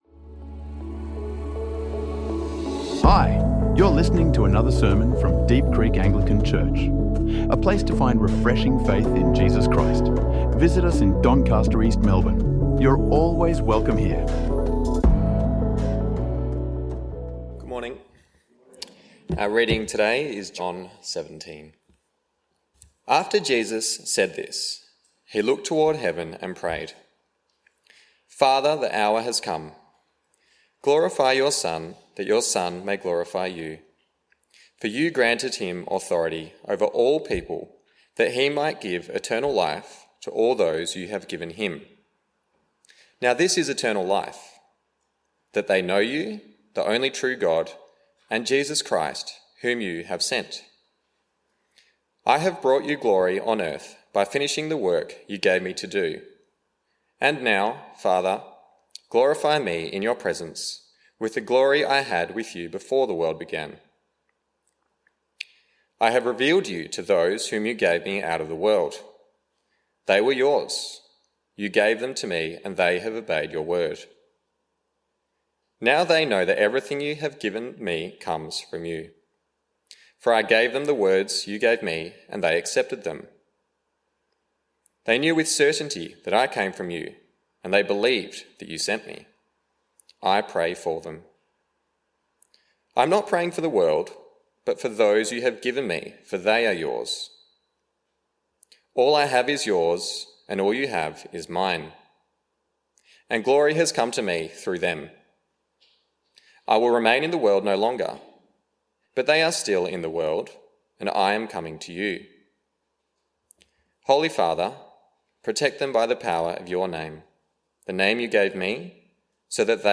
Jesus Prays For Us | Sermons | Deep Creek Anglican Church